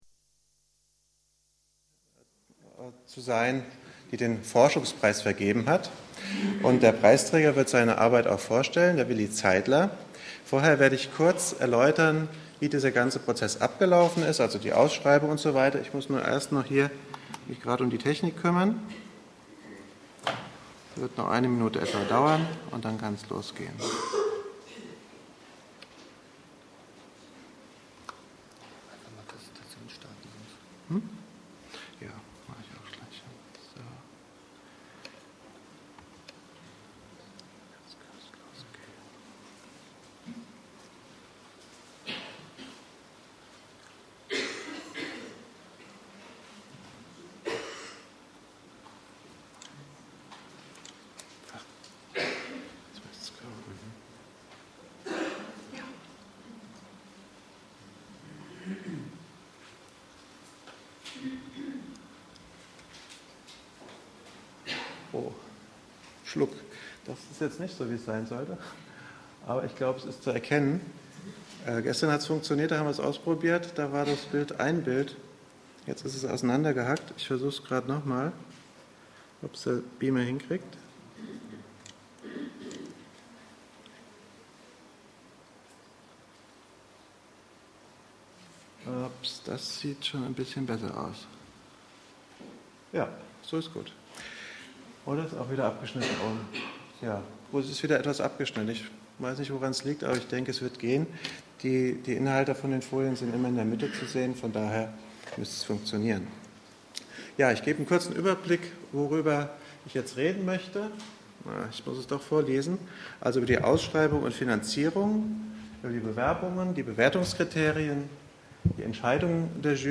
Vergabe des 1. Forschungspreises der SMMR - Yoga Kongress 2007 ~ Yoga Vidya Events Podcast